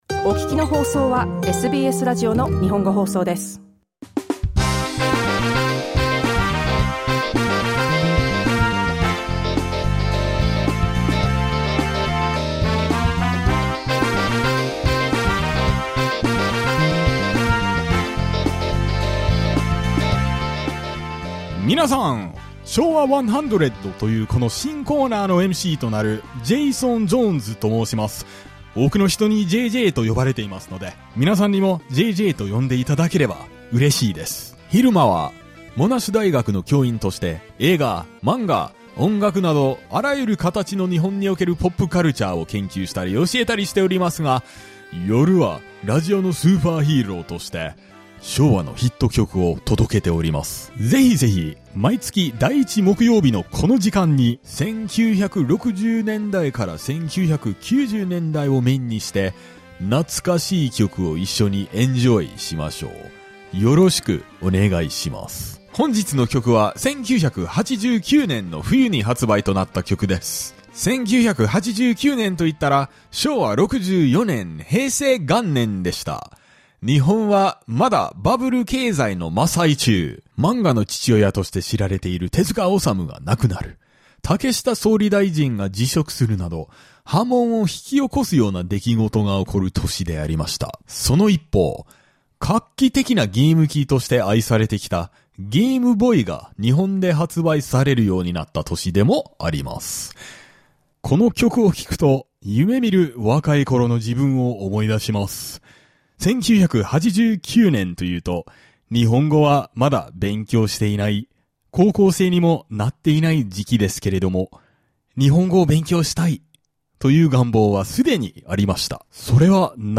SBS Japanese's music segment 'Showa 100'